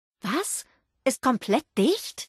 Kategorie:Fallout 76: Audiodialoge Du kannst diese Datei nicht überschreiben.